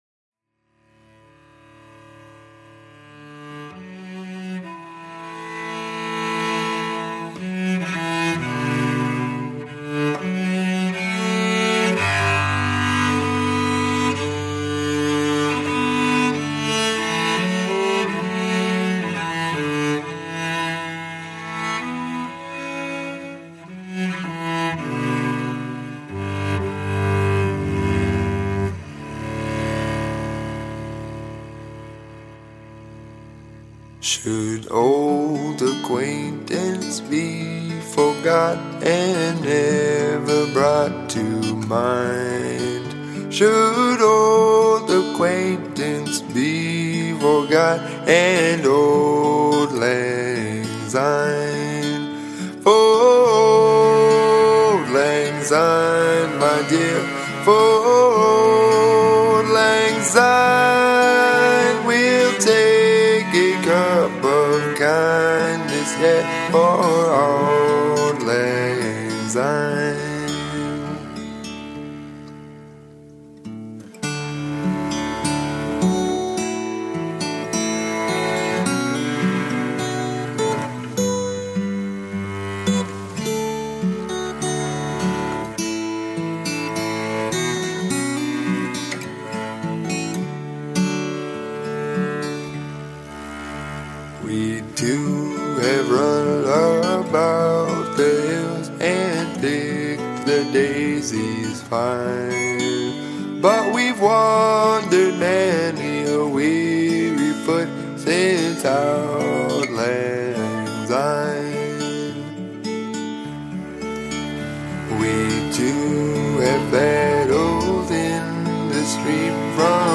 songwriting duo
traditional track